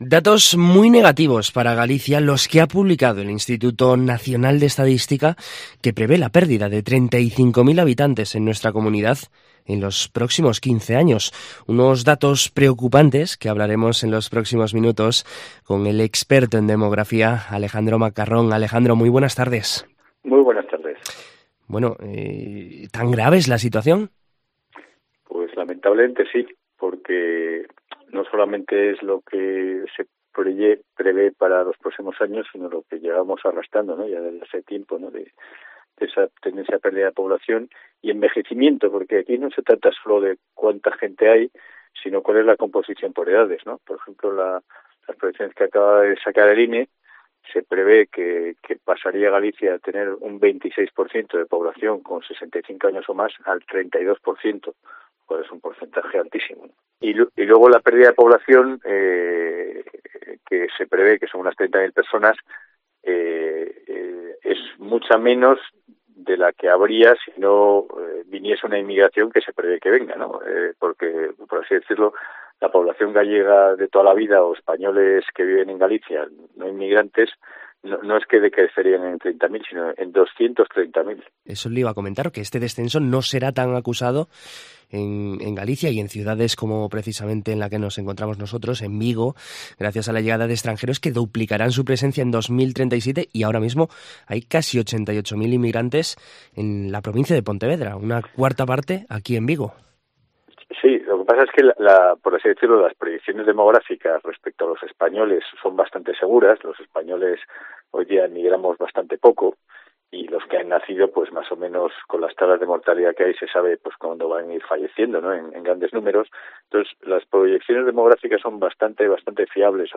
En COPE Vigo conocemos un poco más sobre estos datos publicados por el INE con el experto en demografía